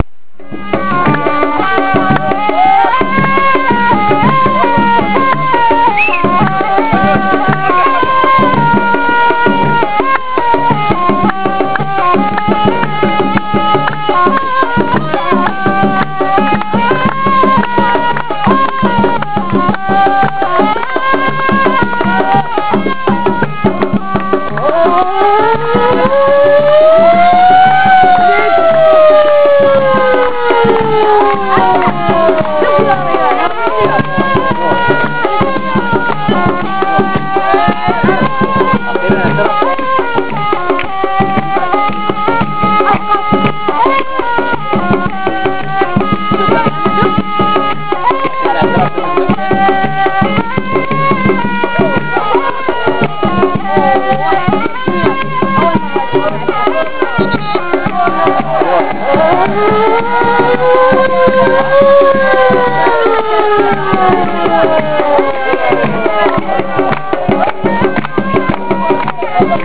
تفضلو يا اخوان اهازيج للنادي الاهلي:
ثانيا اهازيج الجمهور ,قم بحفظها (save traget as):